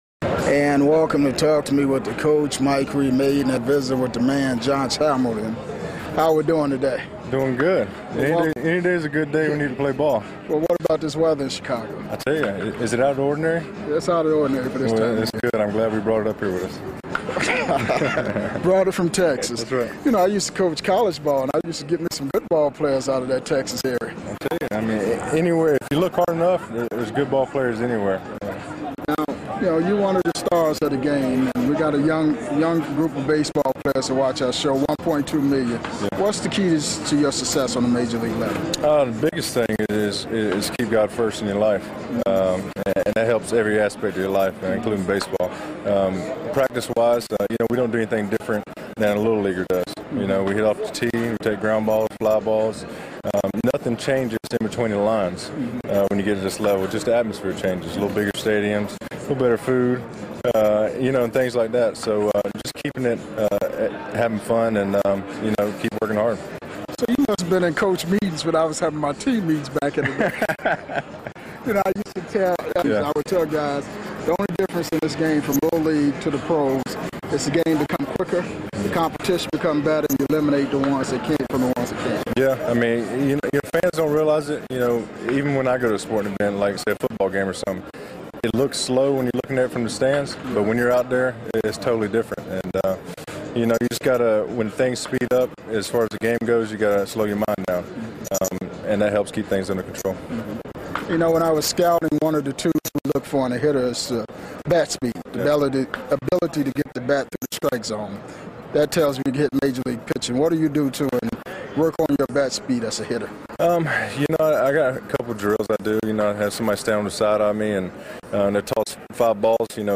full uncut classic MLB interviews